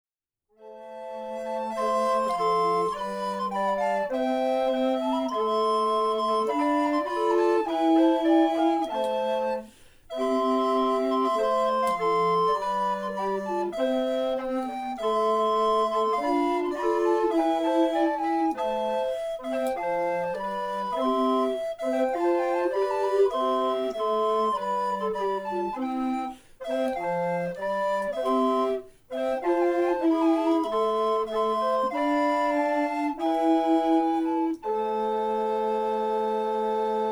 Pastime Consort – Early music for receptions, Churches, drama, private functions and other opportunities.
The Pastime Consort is a recorder consort which has played together for many years in venues from Bard on the Beach to Farmers’ Markets.
Flexible, pure-toned, expressive, a recorder consort can suit any occasion.
We are a group of up to 8 players, all amateur and playing for the love of music and friendship.